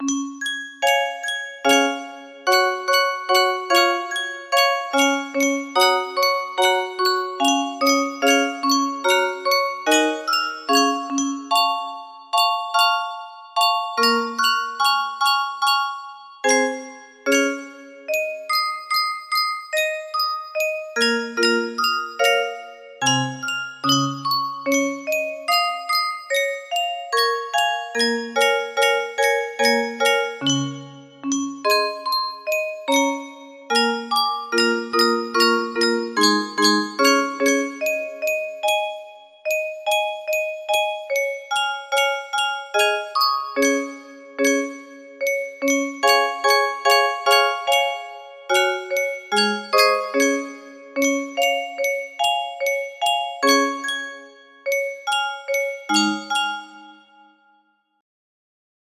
Full range 60
Imported from MIDI from imported midi file (18).mid